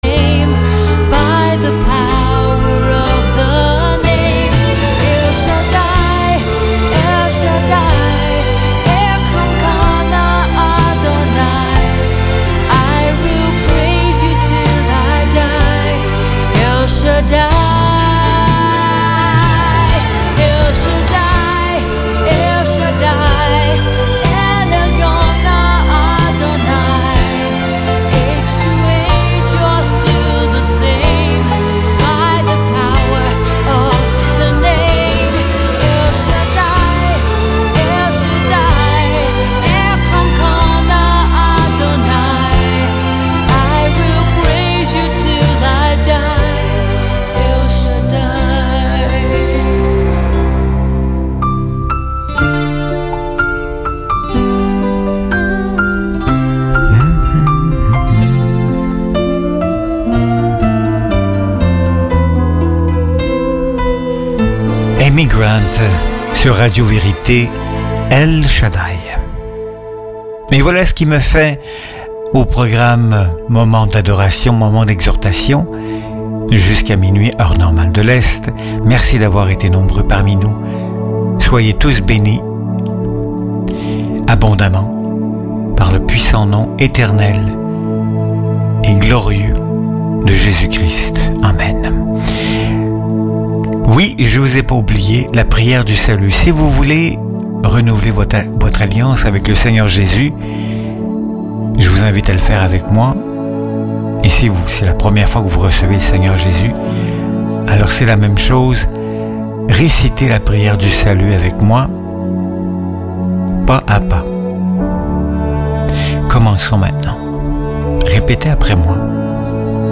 PRIÈRE DU SALUT - SALVATION PRAYER